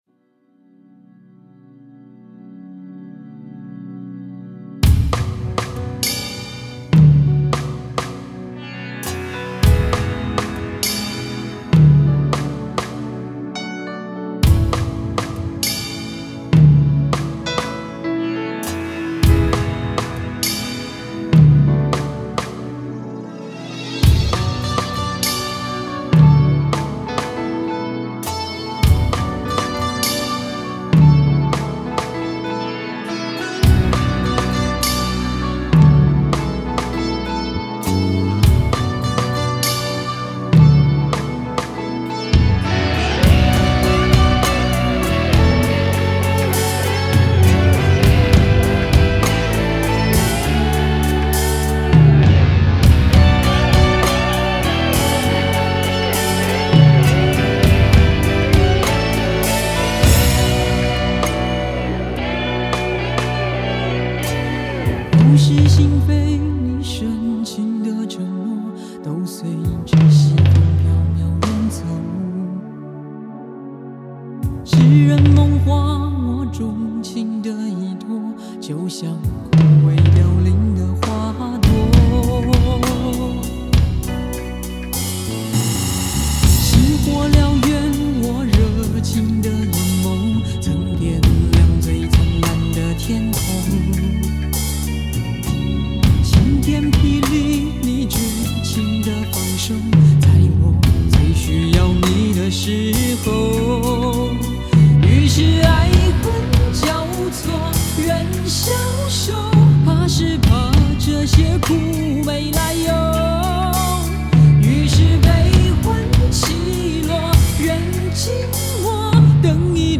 架子鼓